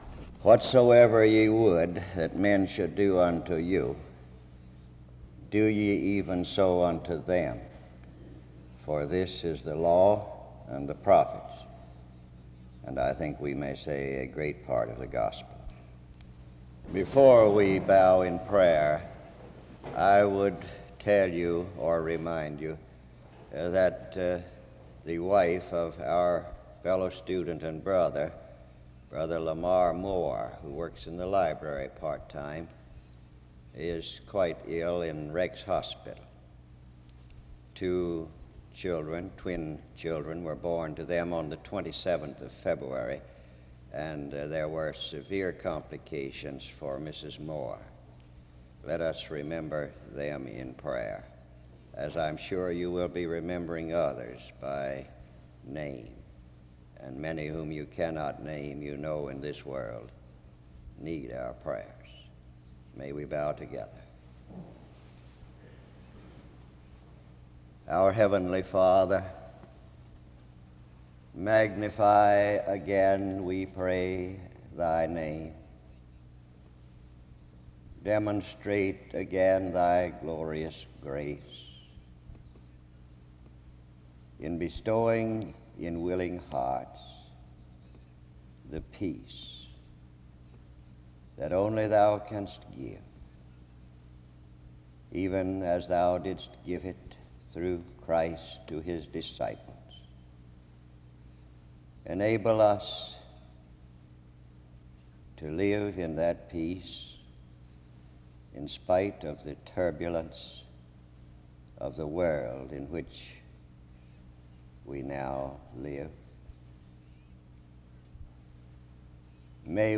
The service begins with the reading of Matthew 7:12 (00:00-00:15), a prayer update (00:16-01:01), and prayer (01:02-05:04).